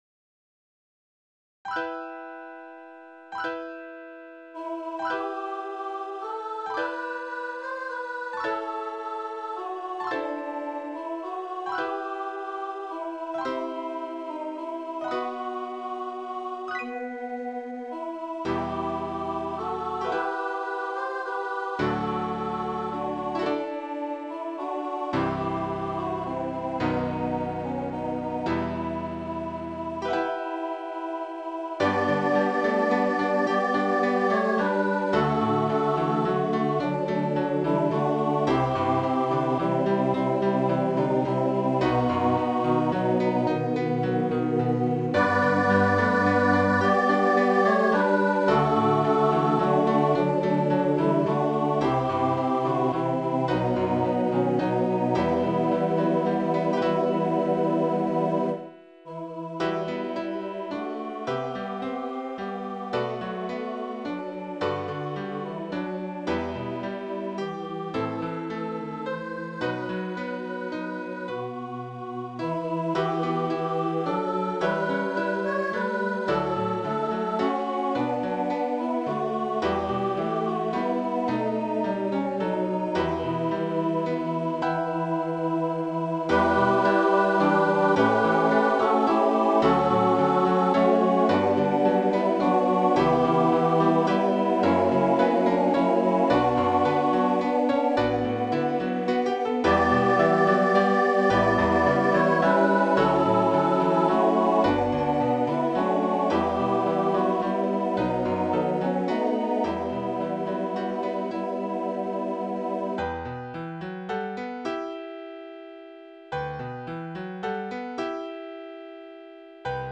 What Child Is This, SATB (by Bonnie Heidenreich -- SATB, Vocal Solo)
The first one features a soprano solo for the second verse.
In the second one, the choir is expanded to SATB throughout.